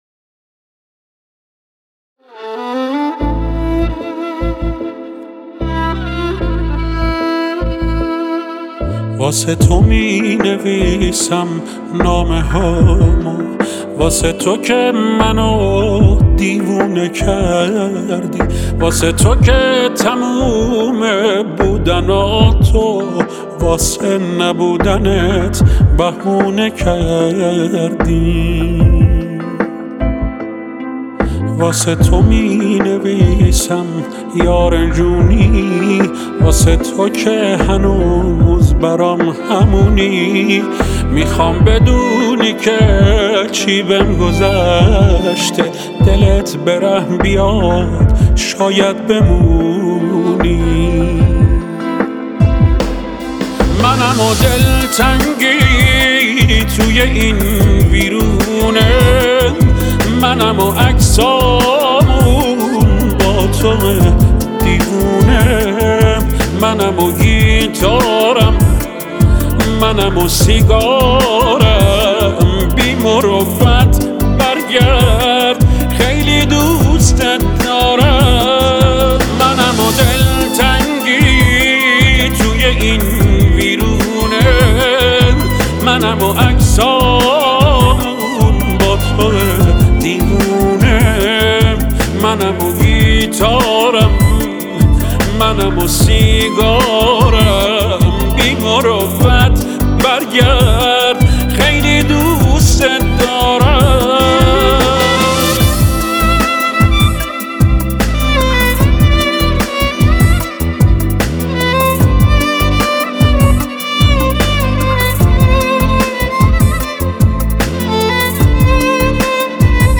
ویولن